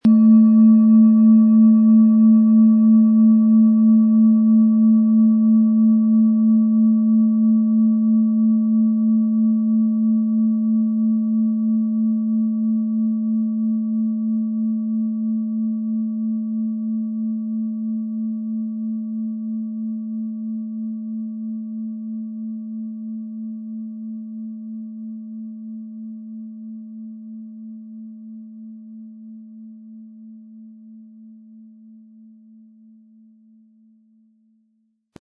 • Mittlerer Ton: Hopi-Herzton
Um den Originalton der Schale anzuhören, gehen Sie bitte zu unserer Klangaufnahme unter dem Produktbild.
PlanetentöneThetawelle & Hopi-Herzton
MaterialBronze